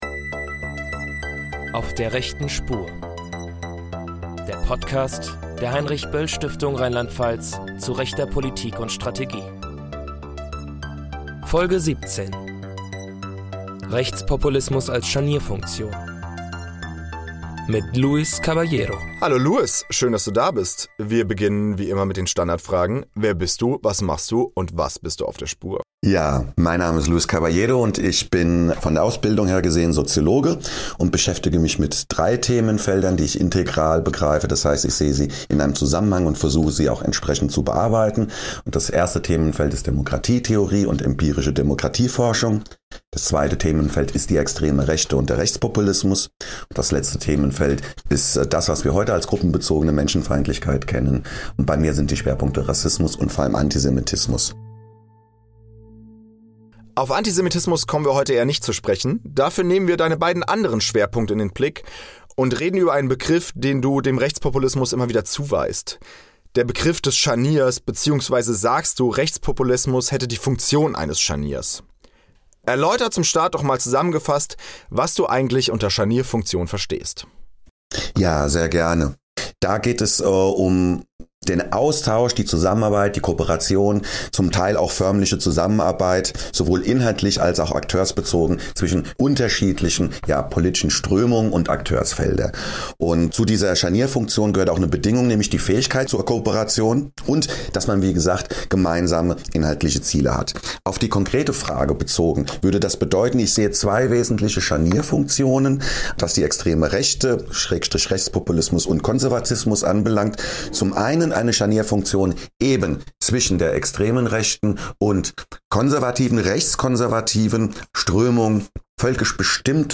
Wir sprechen in dieser Folge mit dem Sozialwissenschaftler